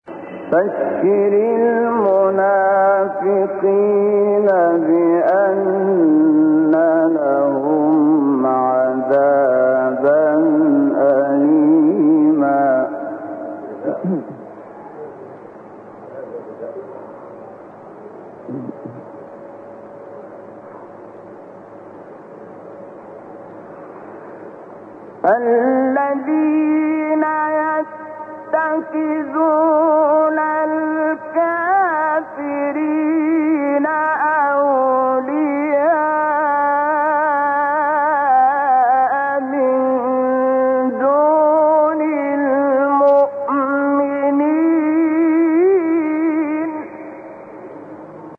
گروه شبکه اجتماعی: فرازهای صوتی از تلاوت قاریان برجسته مصری را می‌شنوید.
مقطعی از شحات محمد انور/ سوره نسا در مقام صبا